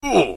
hurt.ogg